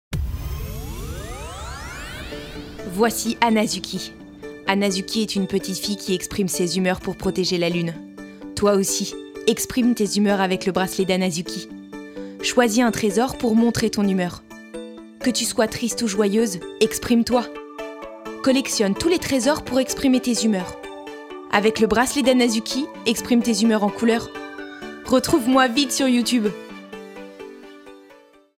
voix pour enfants
Voix 15 - 25 ans